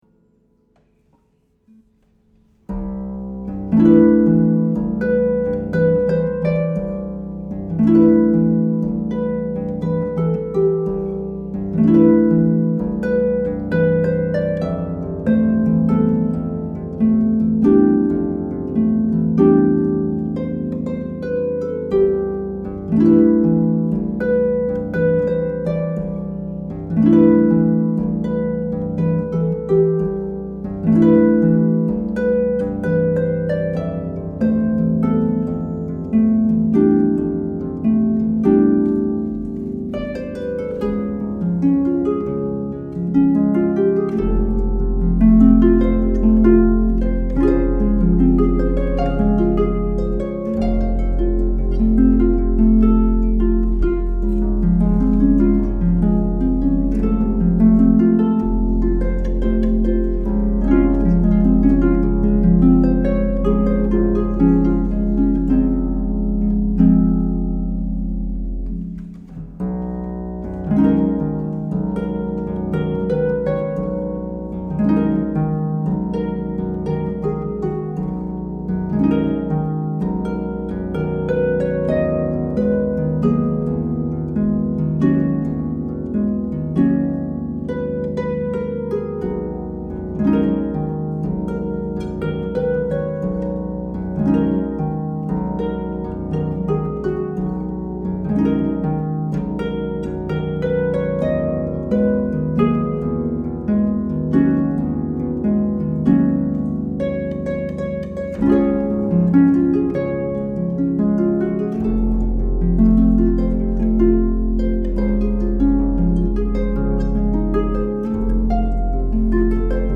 solo pedal harp